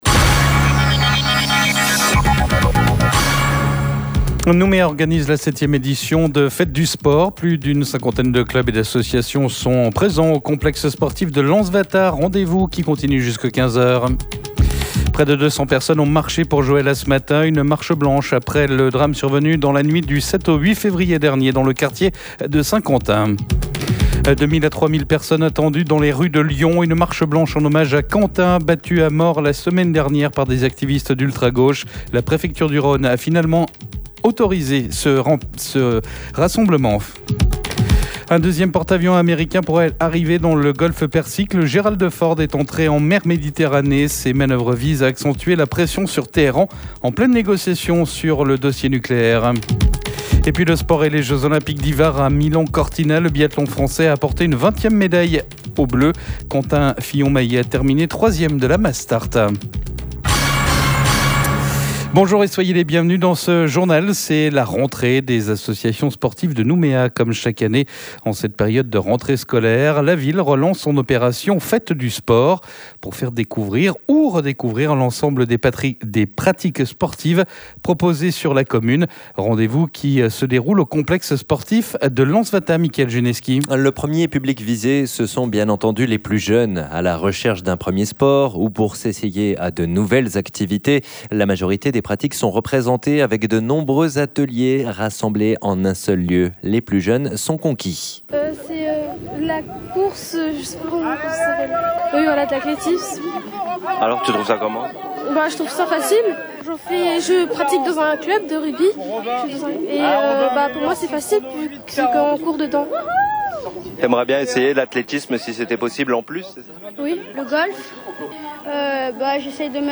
Info-weekend, votre grand journal du weekend, pour tout savoir de l'actualité en Calédonie, en métropole et dans le Monde.